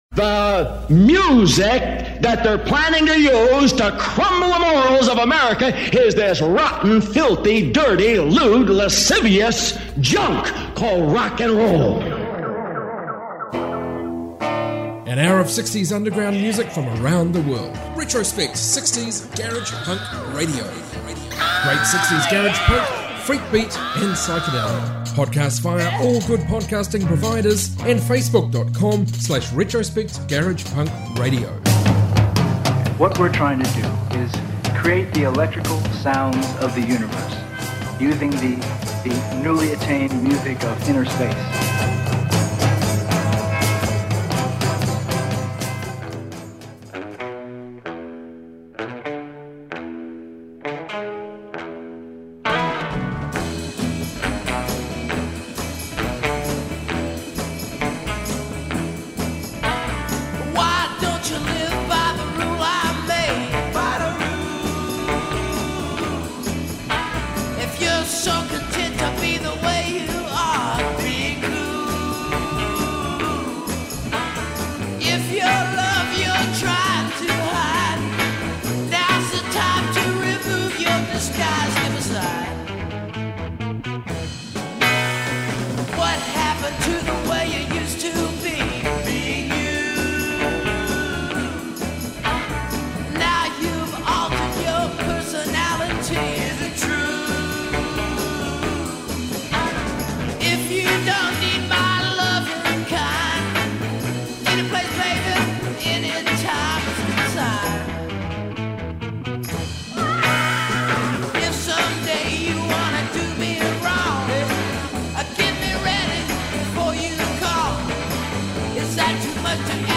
Retrospect 60s Garage Punk Show episode 357